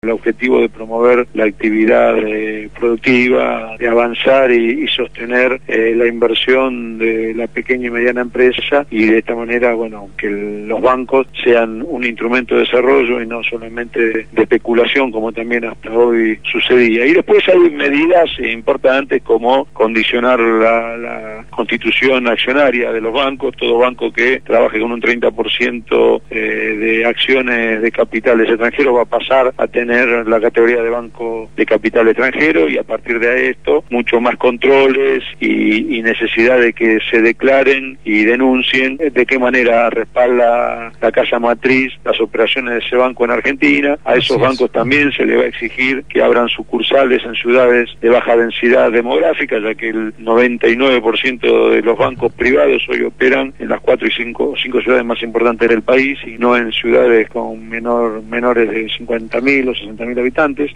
Ariel Basteiro presentó la nueva Ley de Entidades Financieras en Radio Gráfica